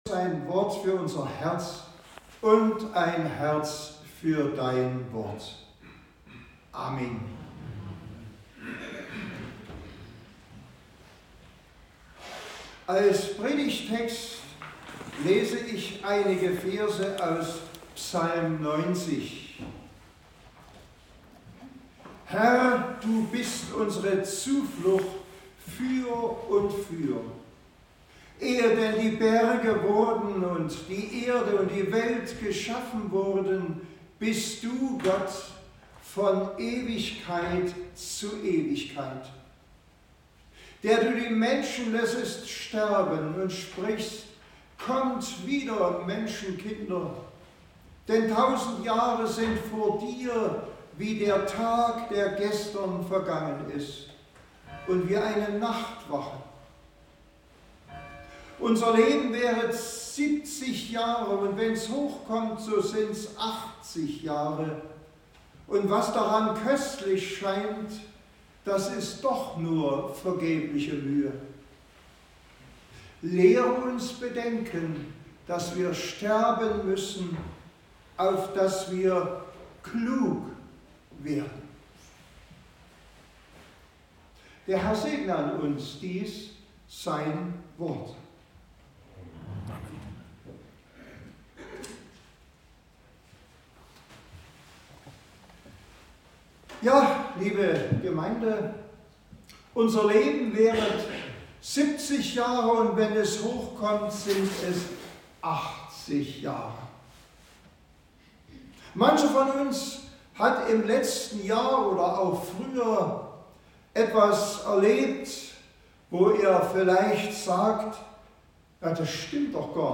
Predigt über die Vergänglichkeit unseres Lebens